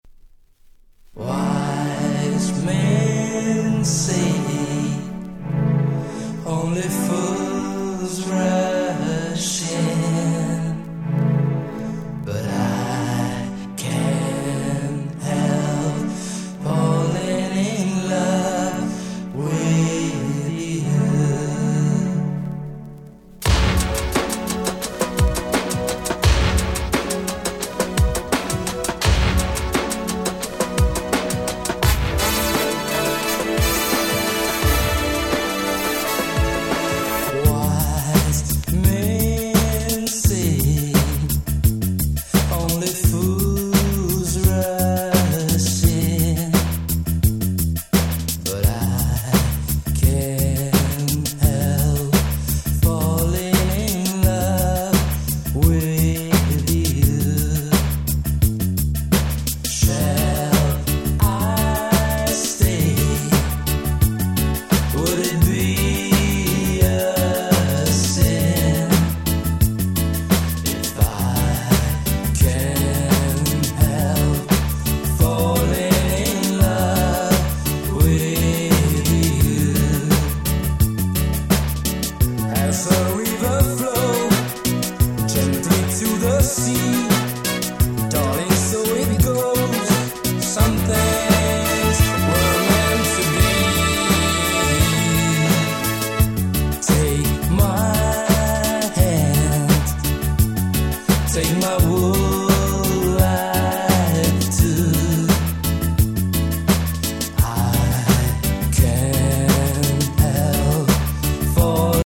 93' Nice Cover Ground Beat/Ace Beat !!